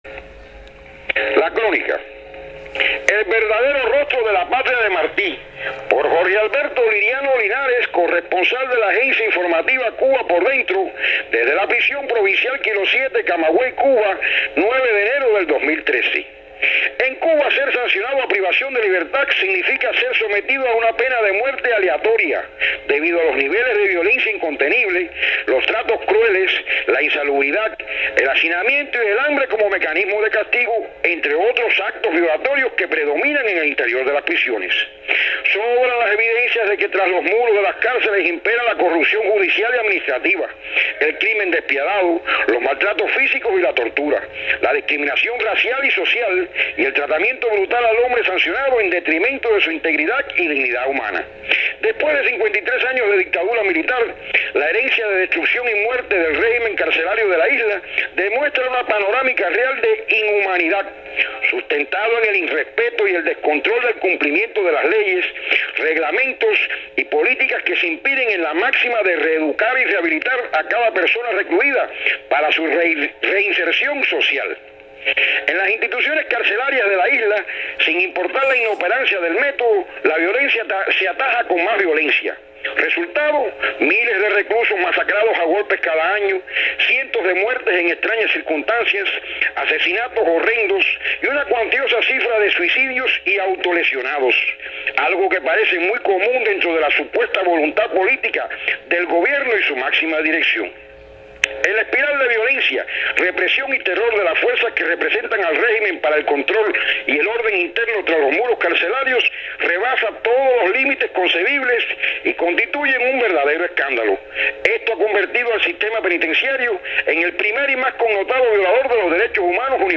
Crónica sobre las prisiones cubanas
desde la prisión provincial “Kilo 7”, Camagüey